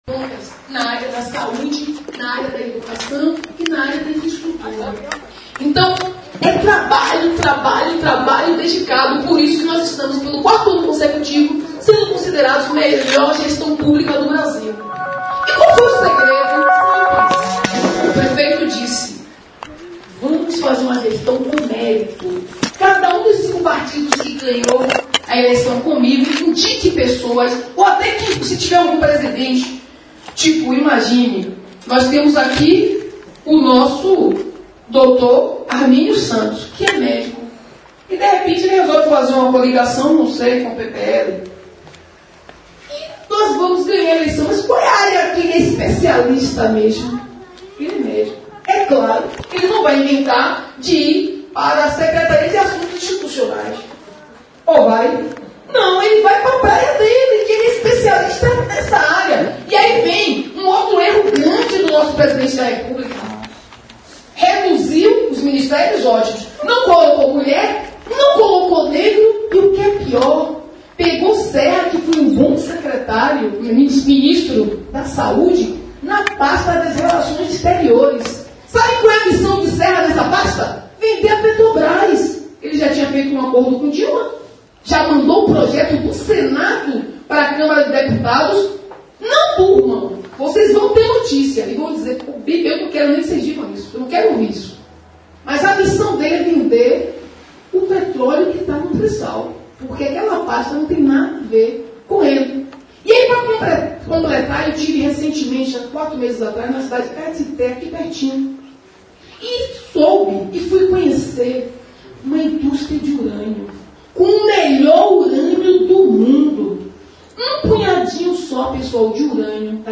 A vice-prefeita de Salvador, ainda que longe do epicentro do furacão, colocou um pouco mais de fogo no estopim, em uma fala contundente que levou Herzem Gusmão a sair antes de o evento terminar, visivelmente constrangido.